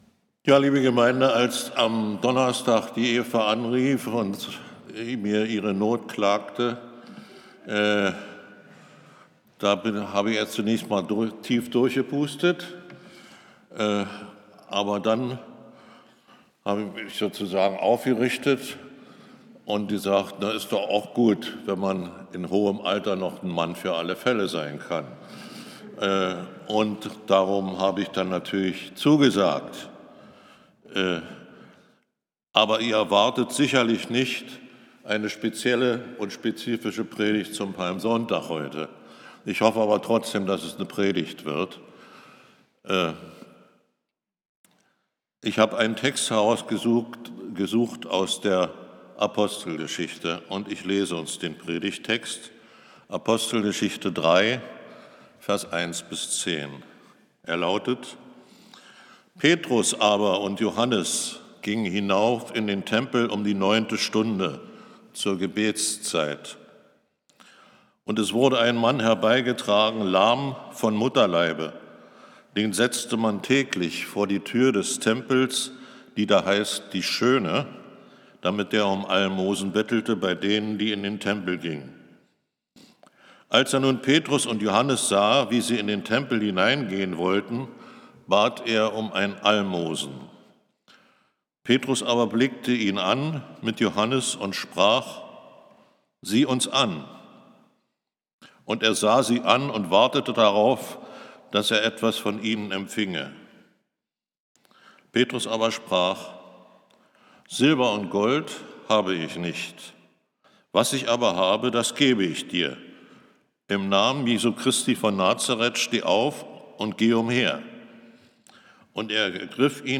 Predigt | Bethel-Gemeinde Berlin Friedrichshain